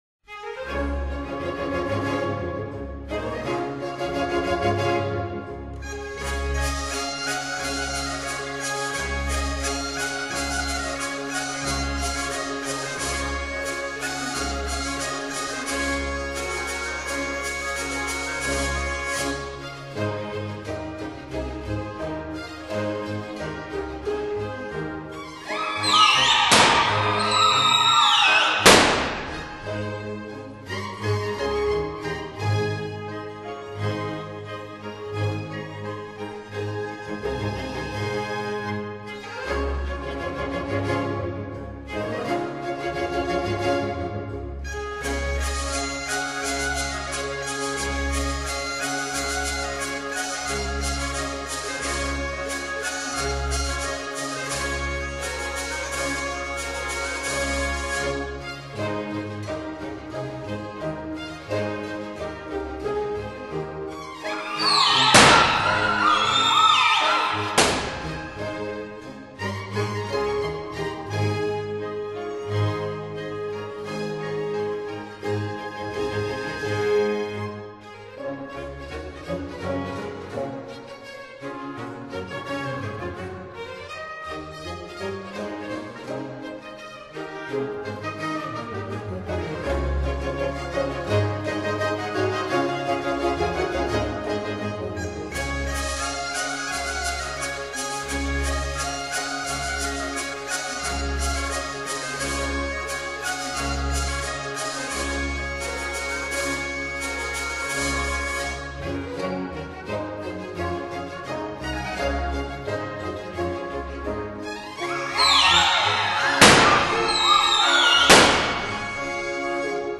(Divertimento in D major)
(orchestra with bagpipe and hurdy-gurdy)